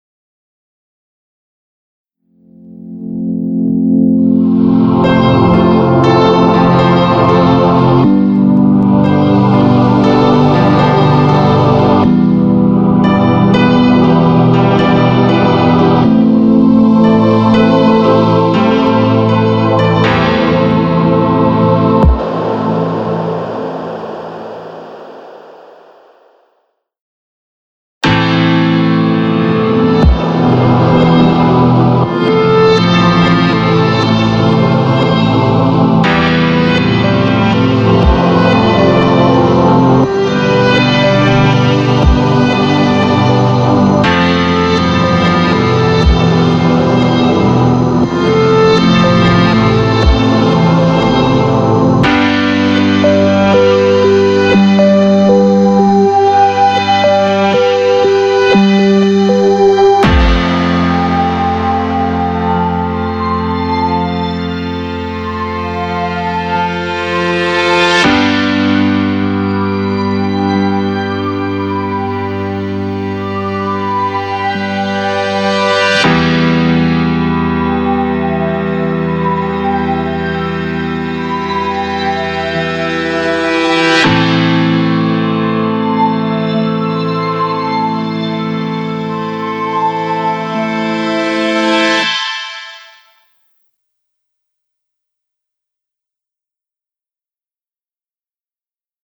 BGM
EDMショート